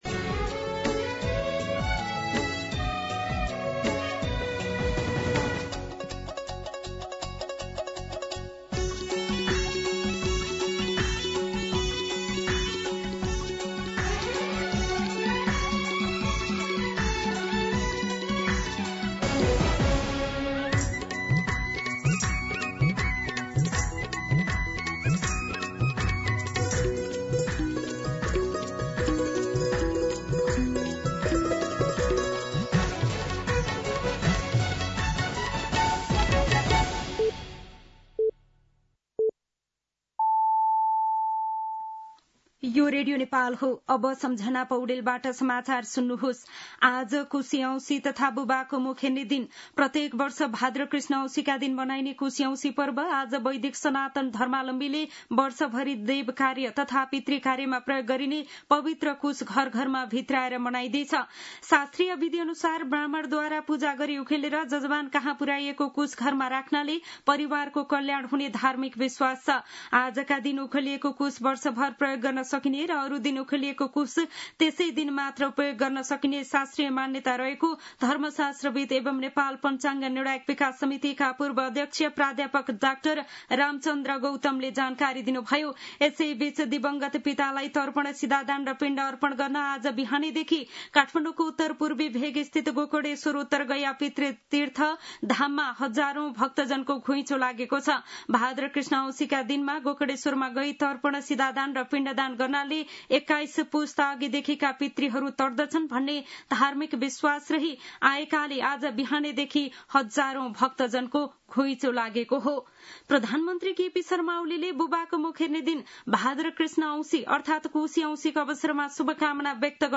मध्यान्ह १२ बजेको नेपाली समाचार : ७ भदौ , २०८२
12-pm-Nepali-News-6.mp3